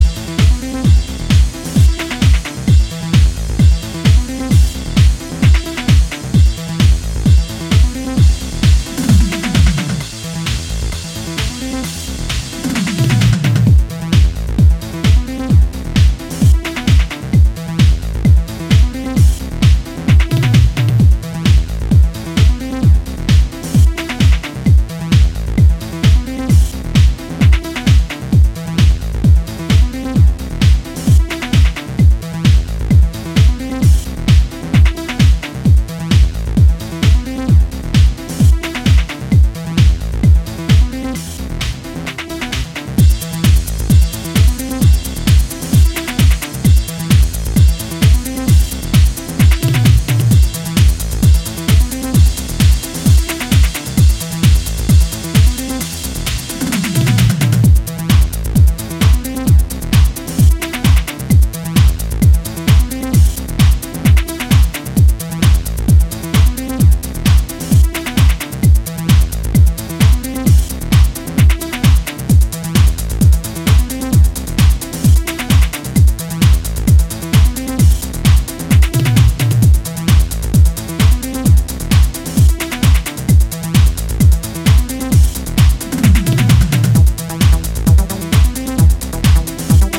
全体的に意識されていそうなミニマルな展開が引力を高めています。